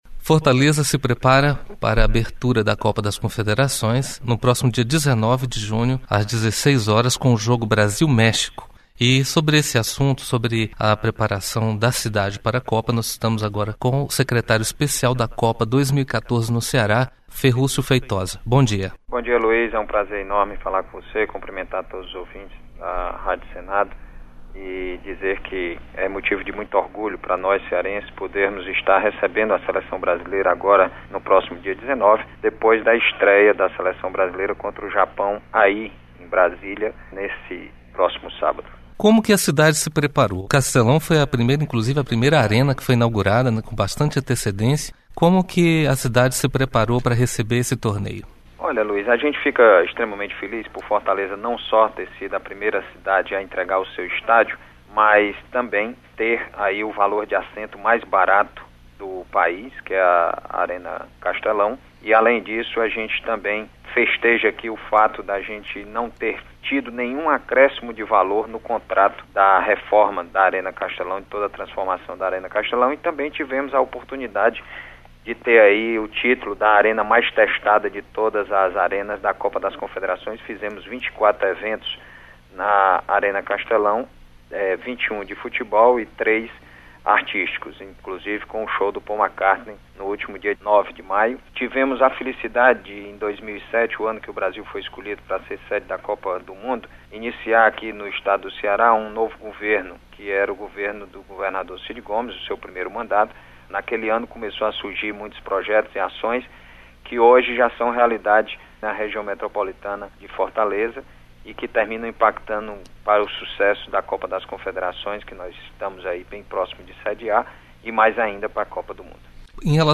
Entrevista: Preparativos de Fortaleza para receber os grandes eventos esportivos
Entrevista: Preparativos de Fortaleza para receber os grandes eventos esportivos Entrevista com o secretário especial da Copa 2014 no Ceará, Ferrúcio Feitosa.